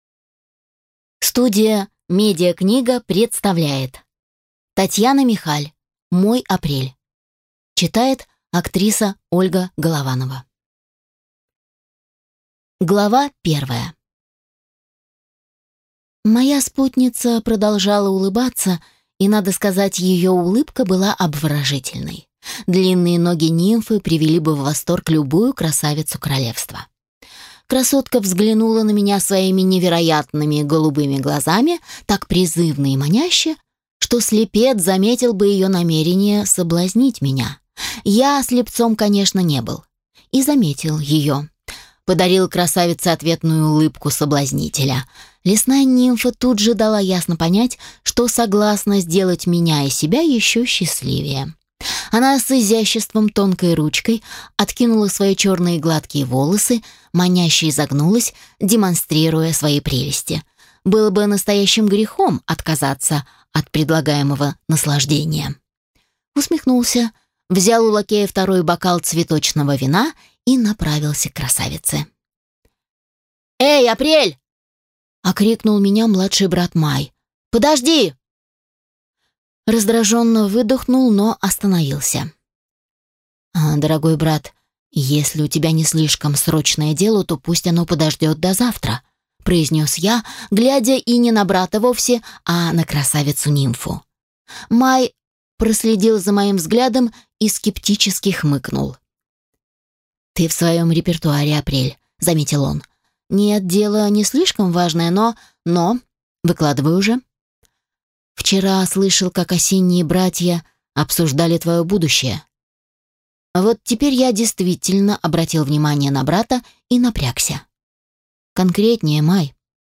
Аудиокнига Мой Апрель | Библиотека аудиокниг
Прослушать и бесплатно скачать фрагмент аудиокниги